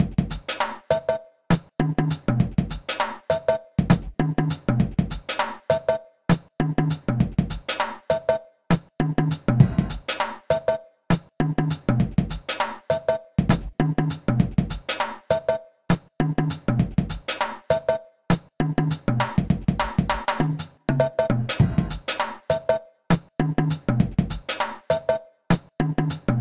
Rhoda is a free drum kit for Hydrogen made from percussive found sounds.
rhoda_drum_example_2.ogg